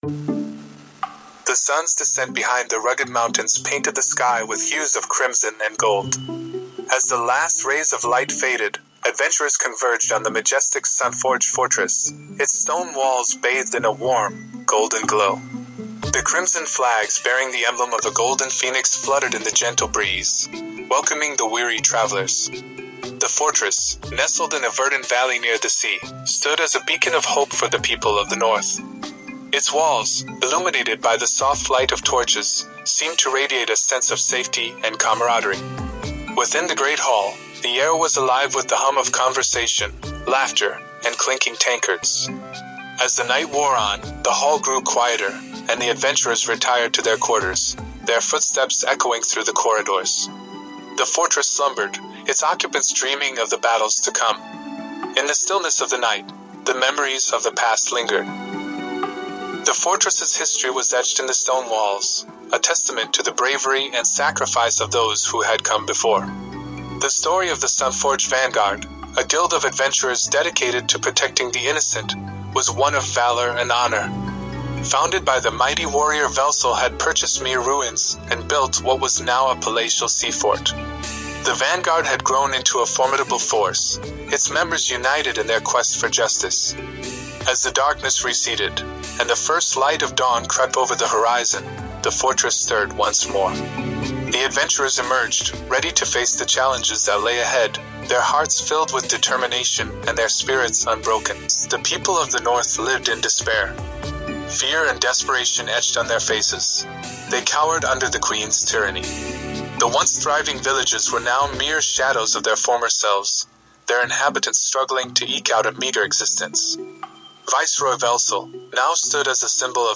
Language: English Format: Audio Book Continuity: Serial Writing: Scripted Voices: Solo Narrator: Third Person Genres: Fantasy Soundscape: Music Not tagged: [Maturity] [Country of origin] [Transcript] Click here to update these tags.